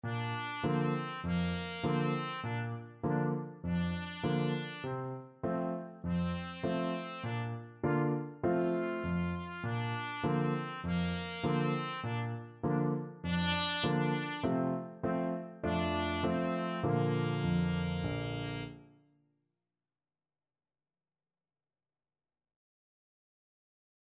Allegretto
4/4 (View more 4/4 Music)
Bb4-F5